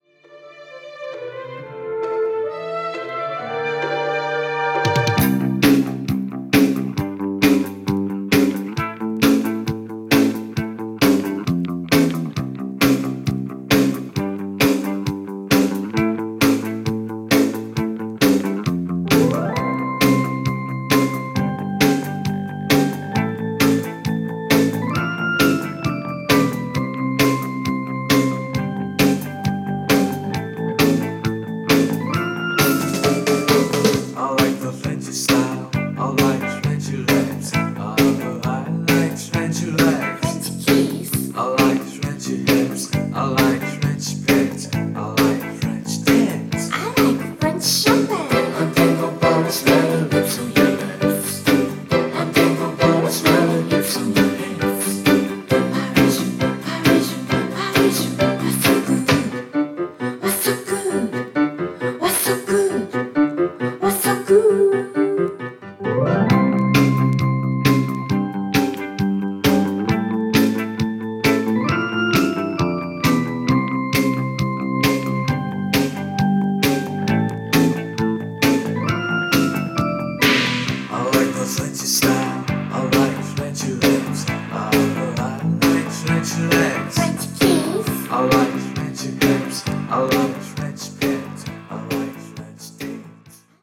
Japanese Synth-pop 12"